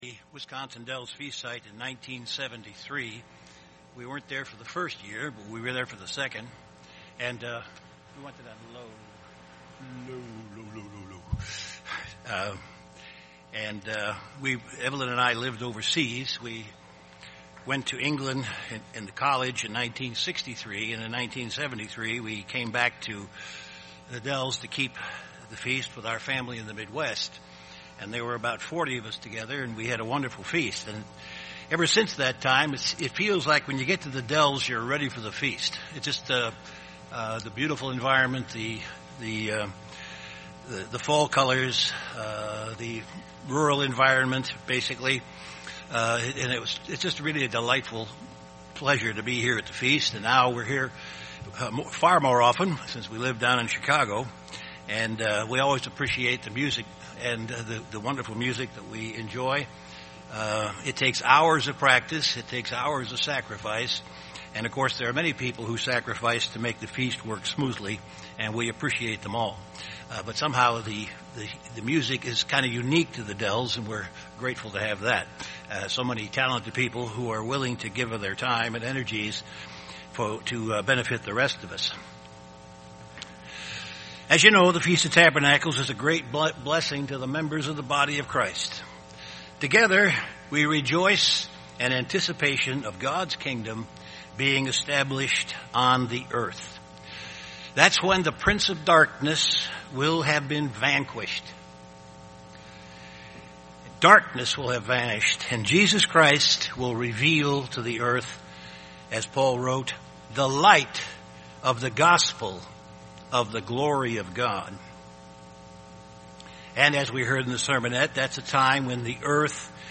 This sermon was given at the Wisconsin Dells, Wisconsin 2012 Feast site.